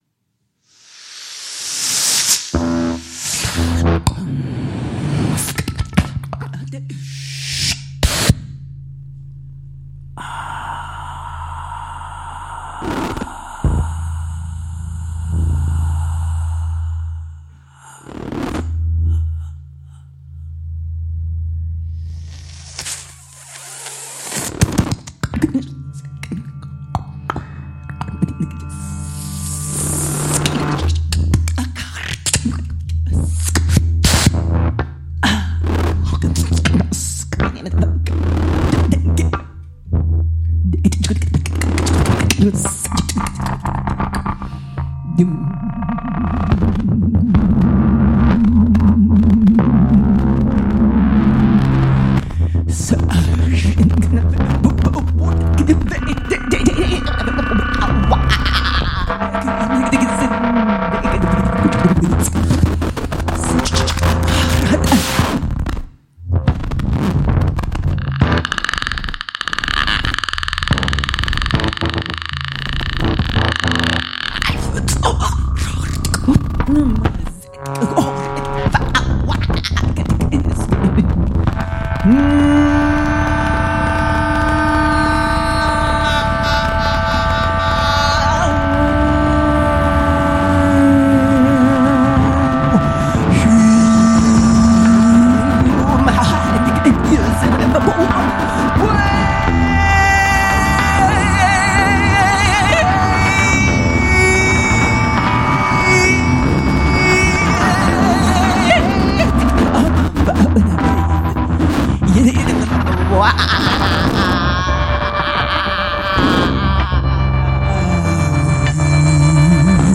voice
electronics
guitar, effects